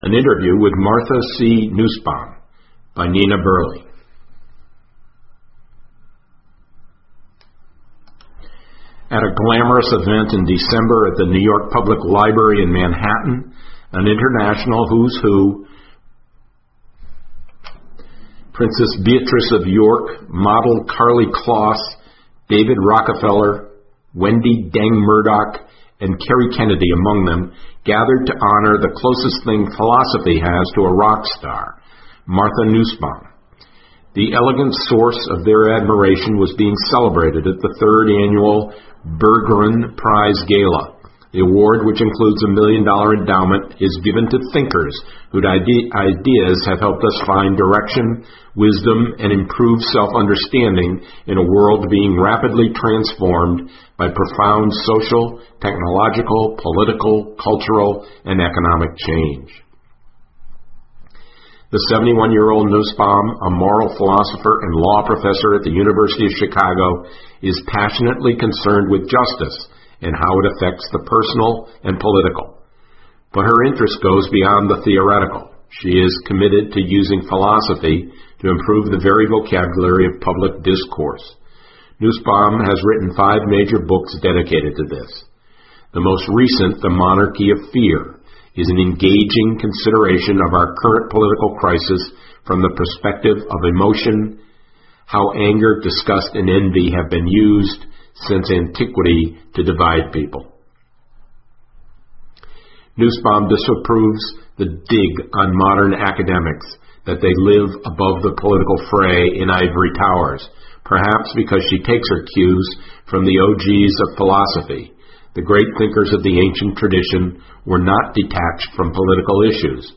新闻周刊:娜斯鲍姆专访:特朗普的恐惧厌恶策略可以被击败(1) 听力文件下载—在线英语听力室